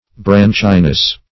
Branchiness \Branch"i*ness\, n. Fullness of branches.